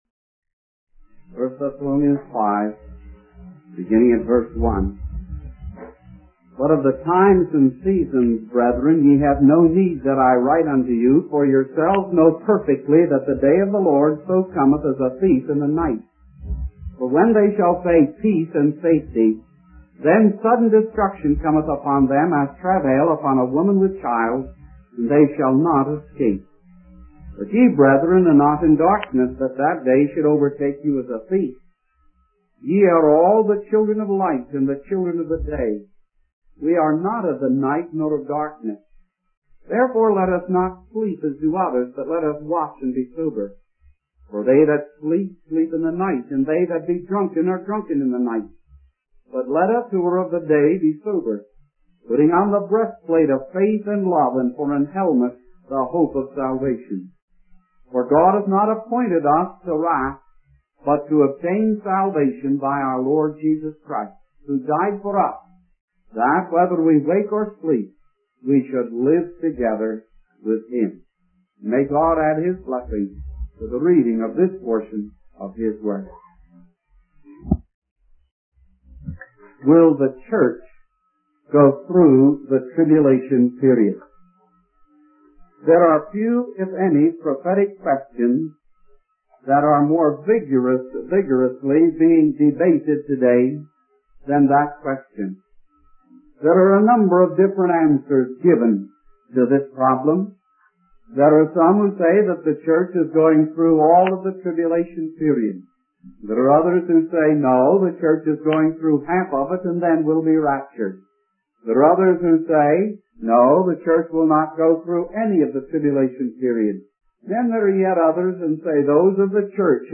In this sermon, the preacher begins by praying for the guidance and understanding of the Holy Spirit. The sermon focuses on the topic of the translation of the church and the coming of the Lord. The preacher emphasizes that the timing of these events is unknown and compares it to the unexpected arrival of a thief in the night.